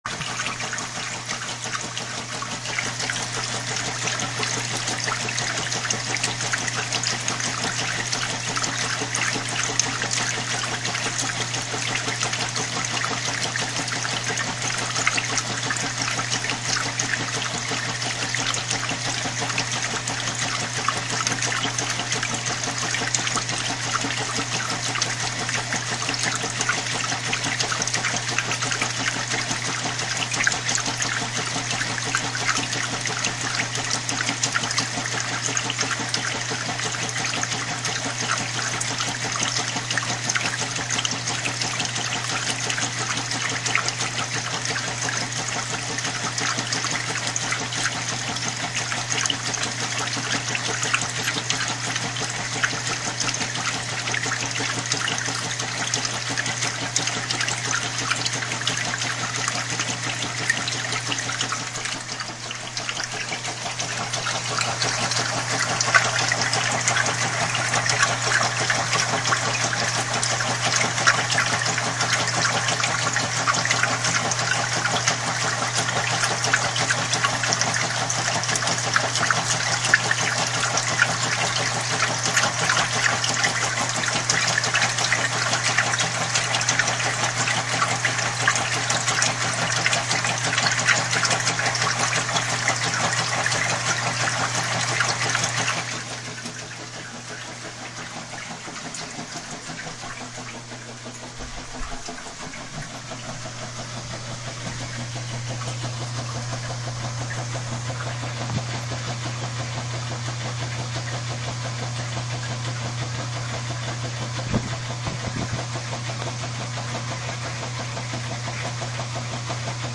洗涤者凝结关闭
描述：在洗衣机的搅拌过程中，水和衣物在洗衣机内部晃动，先是近距离记录（麦克风几乎在机器内部），然后从几英尺外记录。
Tag: 机器 晃动 飞溅 垫圈 洗衣机